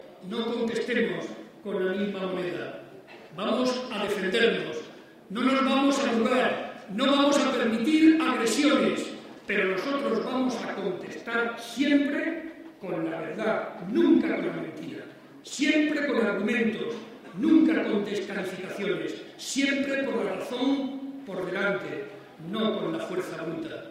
El secretario regional del PSOE y presidente de C-LM, participó en la tradicional comida de Navidad de los socialistas de Albacete.
Cortes de audio de la rueda de prensa